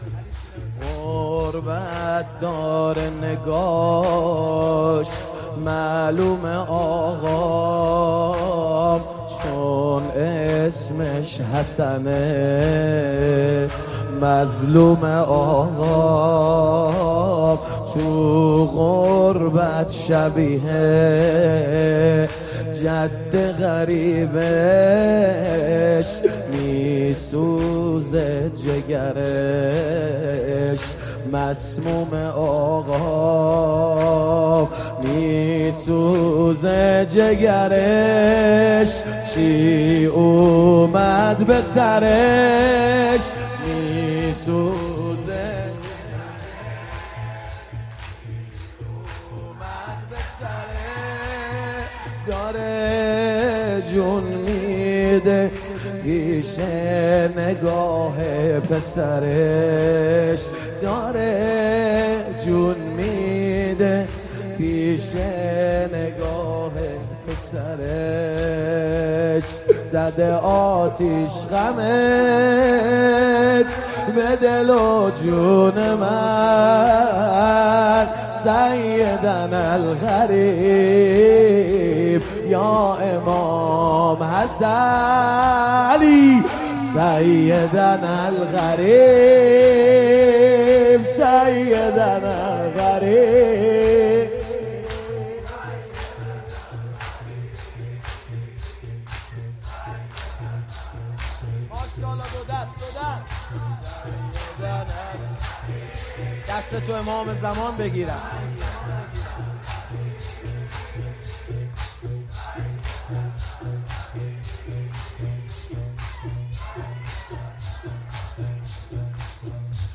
فایل صوت|زمینه| غربت داره نگاش|شهادت‌امام‌عسکری‌علیه‌السلام - هیئت حیدریون اصفهان
واحد|غربت داره نگاش شهادت‌امام‌عسکری‌علیه‌السلام